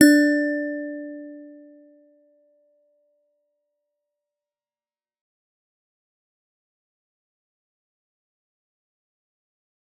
G_Musicbox-D4-f.wav